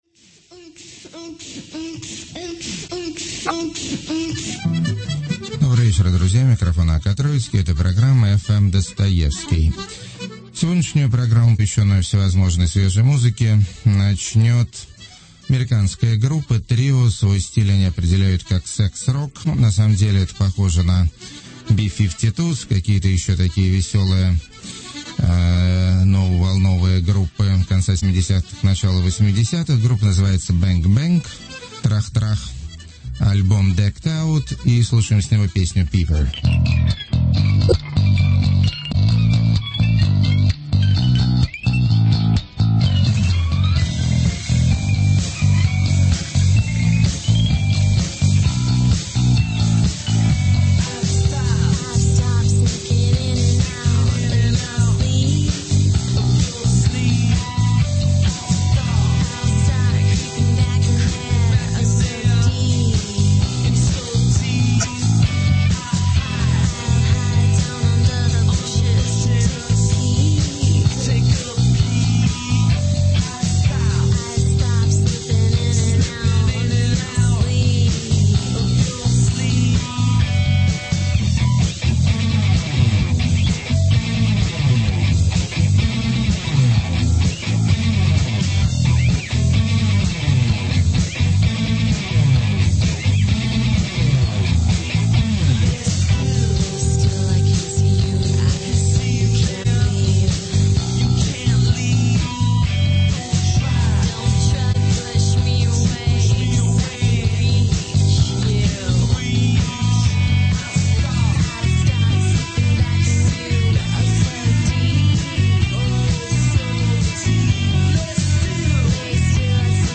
Запись с FM эфира, возможны помехи.